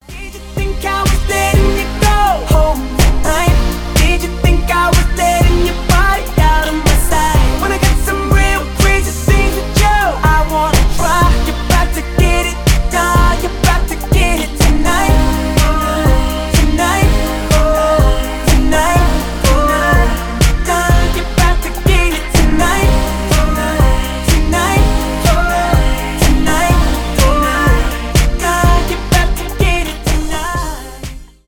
танцевальные , rnb , зарубежные , поп